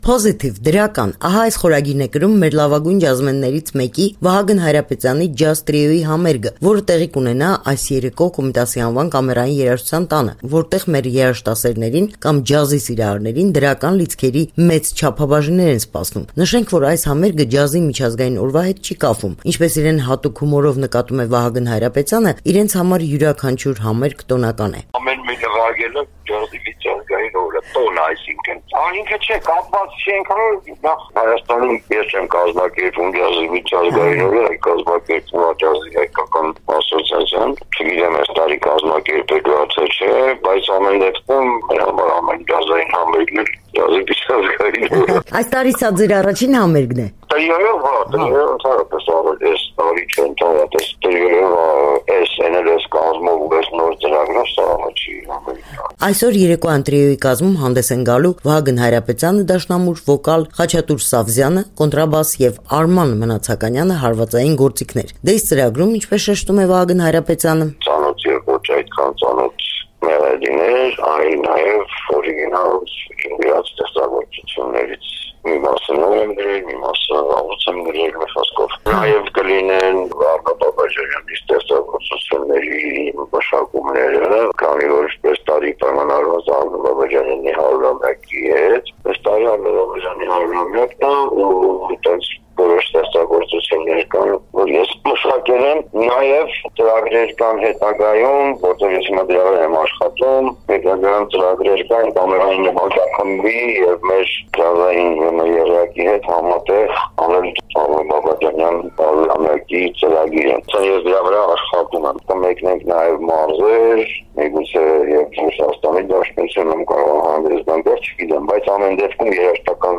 Ջազ համերգ՝ Կոմիտասի անվան Կամերային երաժշտության տանը